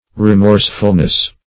Re*morse"ful*ness, n.